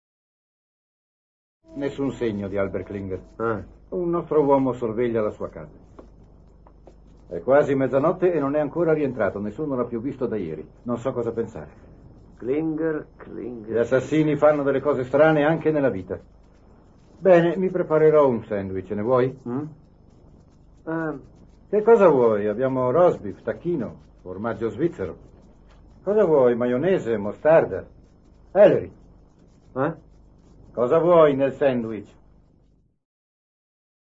dal telefilm "Ellery Queen", in cui doppia David Wayne.